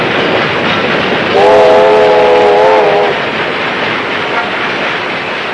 train2.mp3